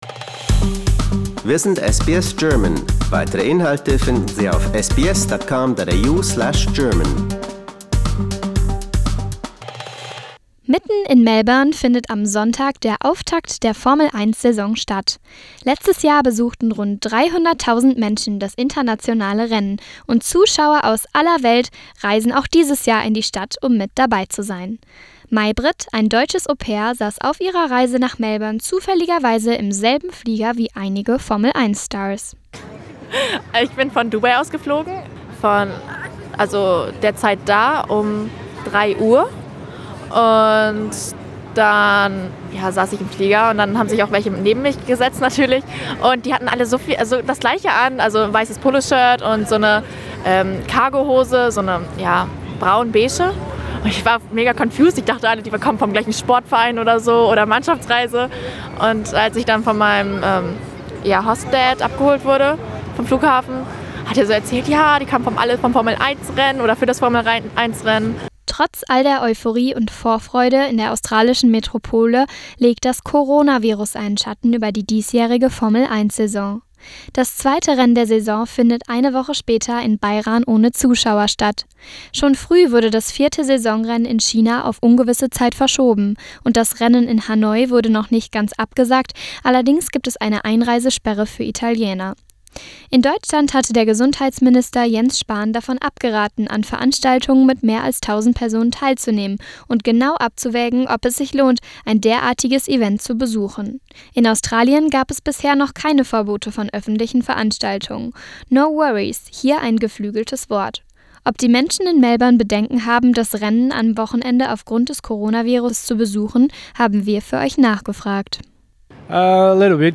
Der GP von Australien soll stattfinden, obwohl Ärzte und am Vorabend der Qualifikation sogar Fahrer wie Lewis Hamilton ihr Unverständnis äussern. Wir fragen deutsche und australische Fans, ob sie Angst vor einer Coronavirus-Ansteckung haben.